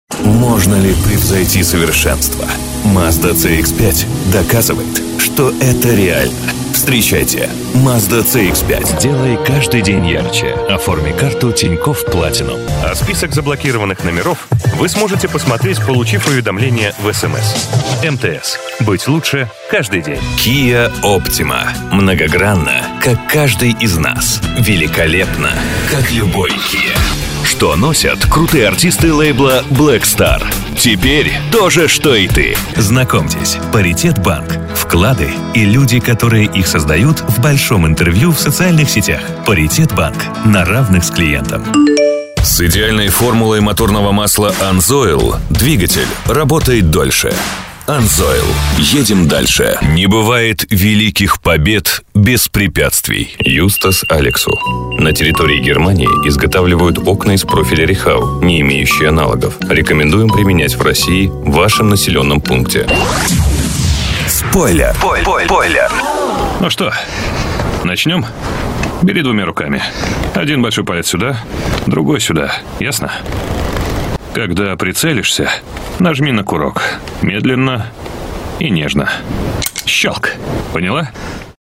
Солидный вариант для представительских аудиорешений. Идеальный голос для закадрового озвучивания.
Тракт: rode nt2000, карта RME babyface Pro, акустическая кабина.